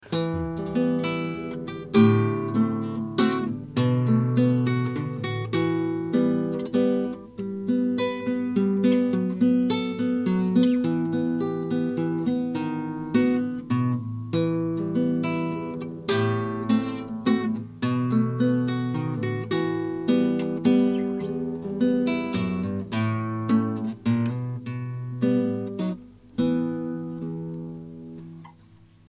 Ashington Folk Club - 01 February 2007
classical guitar